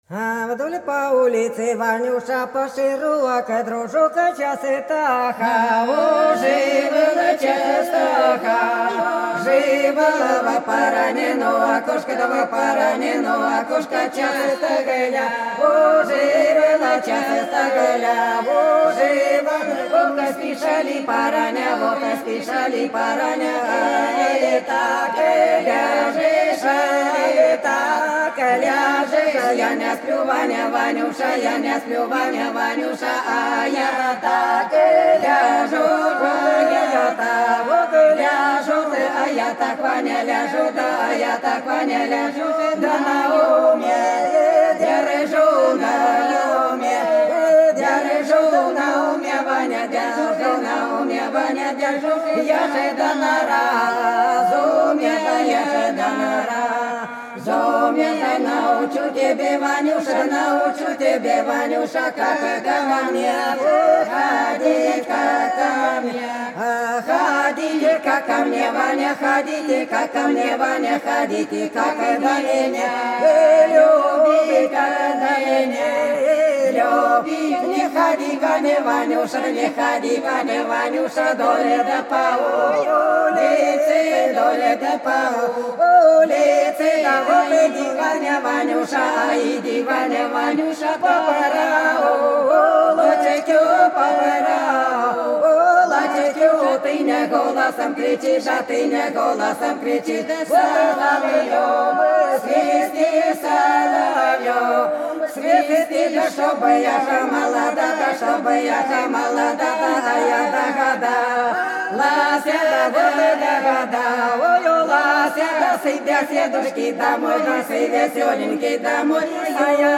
Белгородские поля (Поют народные исполнители села Прудки Красногвардейского района Белгородской области) Вдоль по улице Ванюша часто хаживал - плясовая